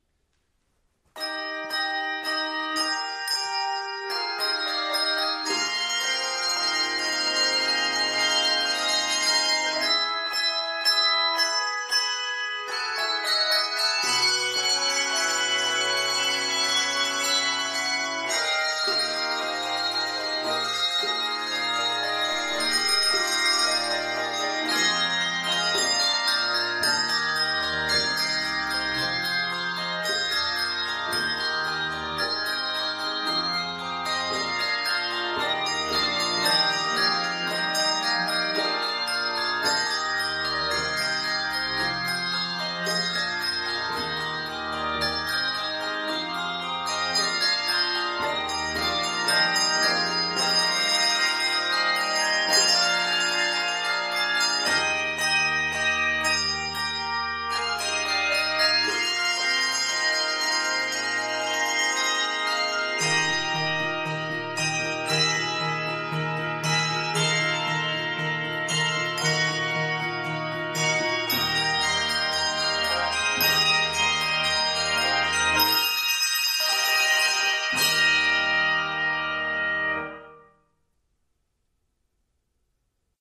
is a bold fanfare
a big-sounding fanfare
Key of Eb Major.